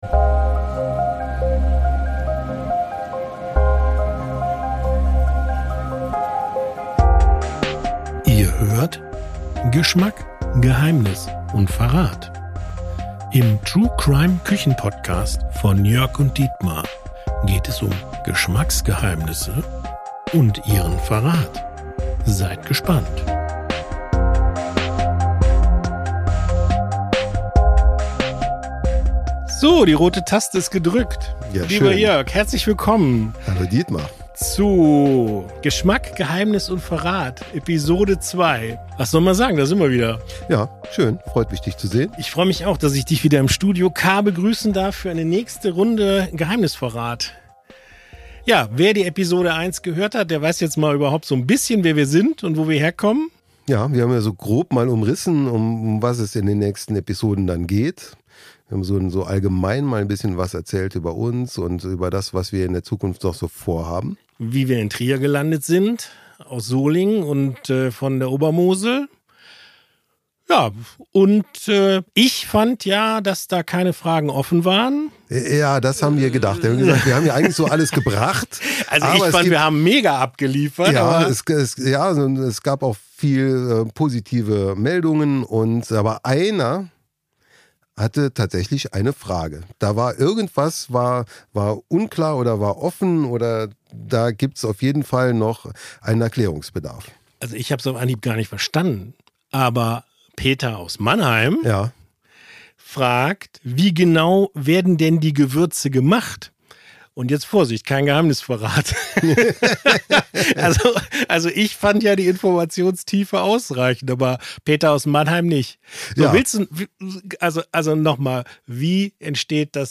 Ein entspannter Talk zwischen einem Könner und einem Kenner.